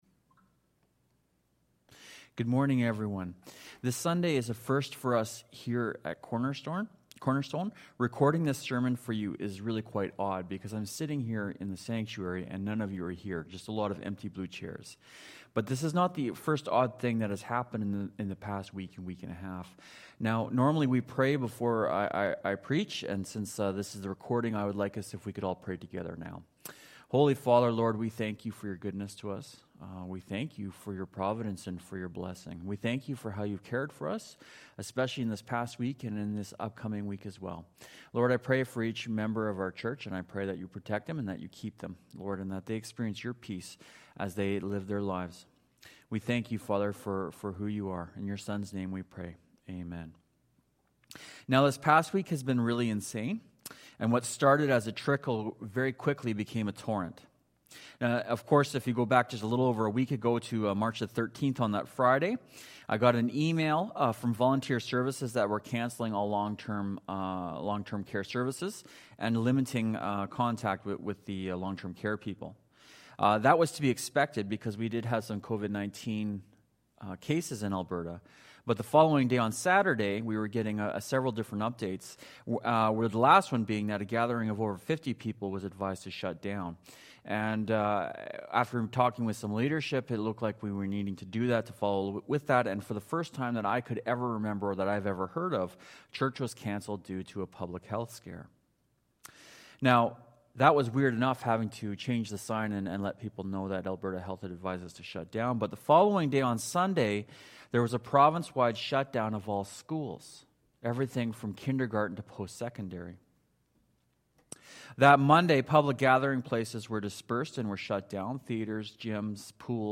Psalm 121 Service Type: Sermon only Bible Text